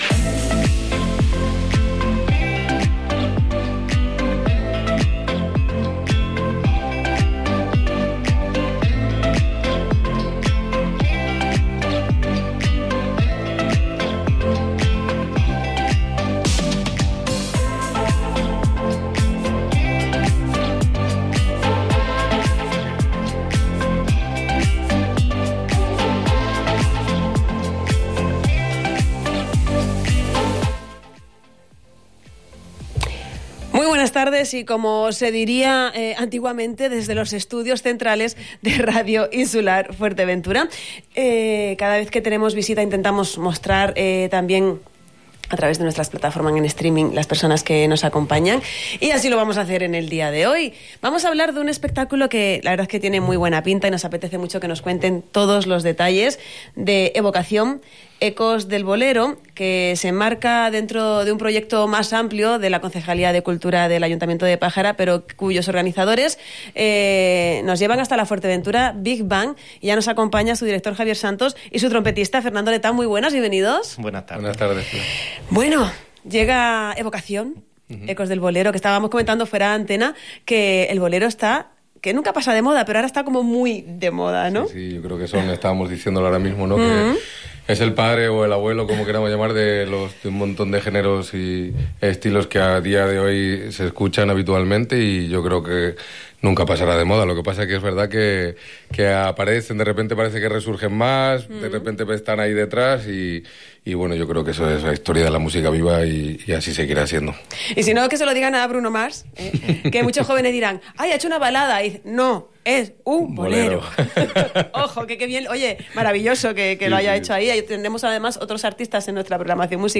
Entrevista-Evocacion-ecos-del-bolero.mp3